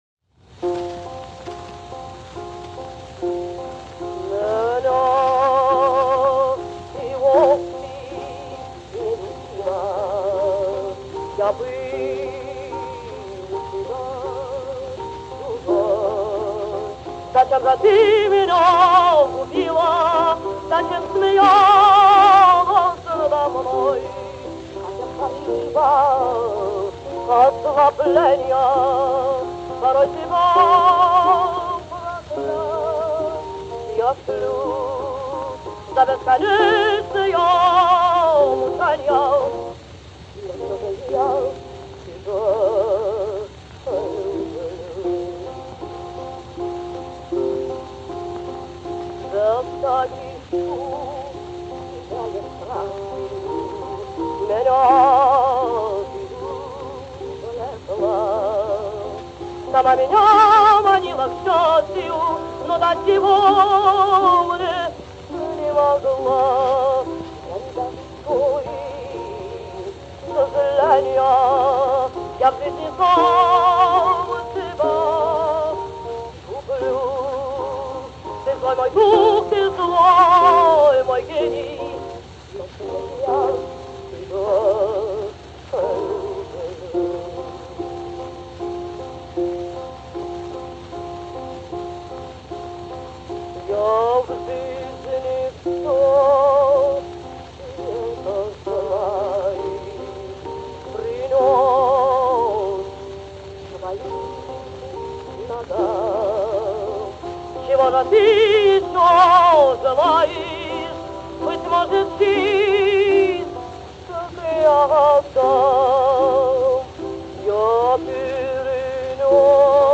Исполнительница русских и цыганских романсов.
Здесь и создалась ее слава. 12 лет служила она украшением хора, и вместе с годами креп и все обстоятельнее становился ее голос — редкое контральто с низкими бархатистыми нотами.